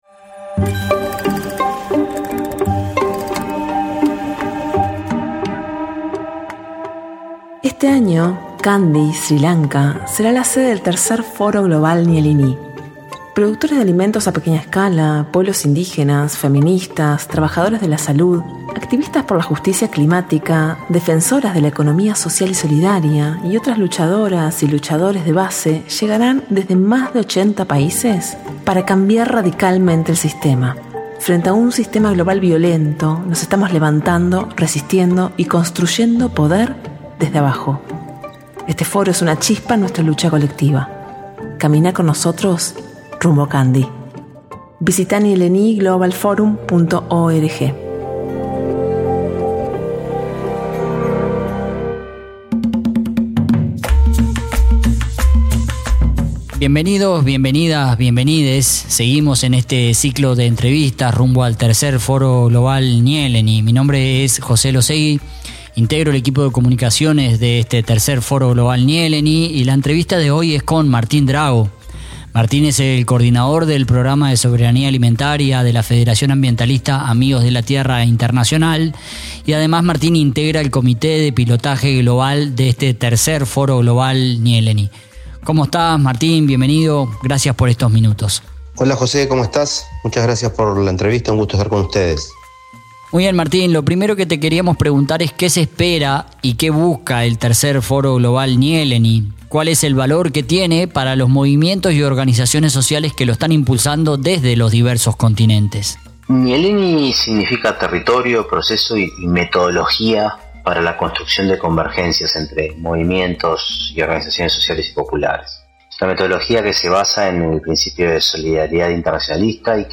Cuenta regresiva para el inicio del III Foro Global Nyéléni.